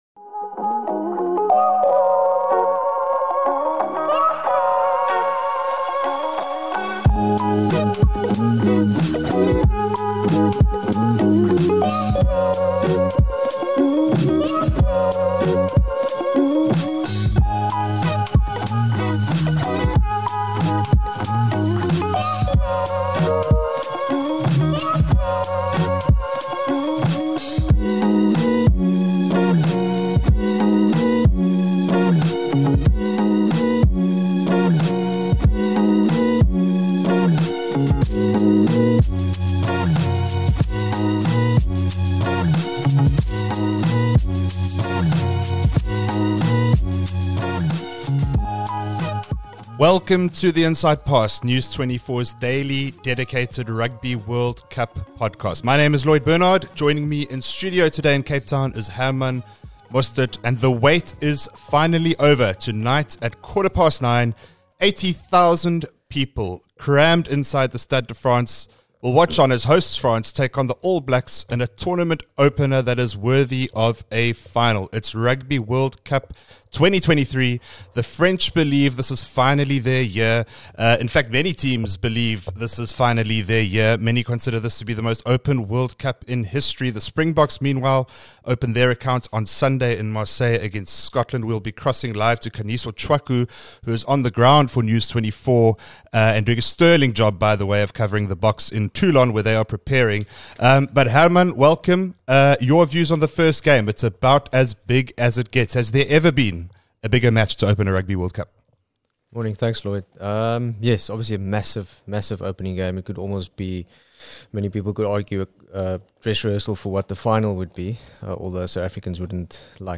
Join News24's team of rugby analysts as they build up to the start of the 2023 Rugby World Cup and discuss the opening weekend's fixtures, including South Africa's opening clash against Scotland.